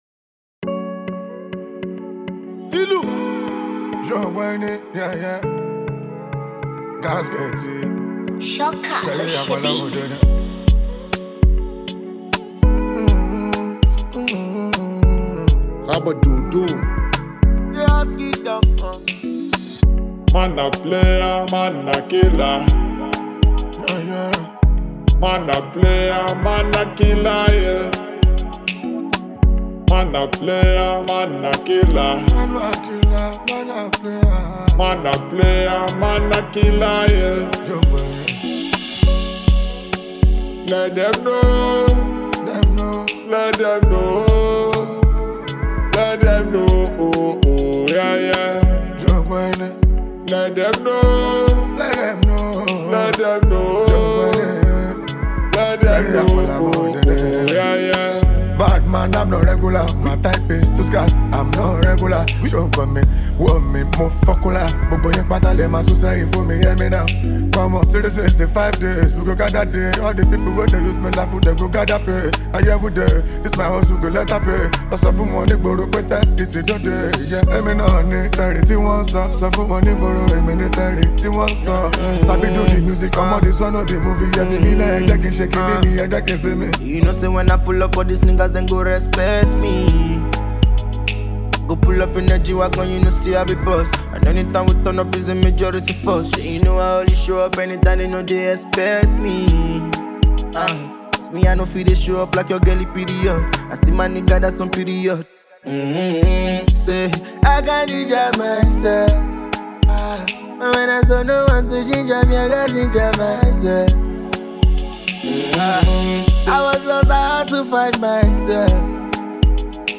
Award-winning Nigerian DJ, Songwriter.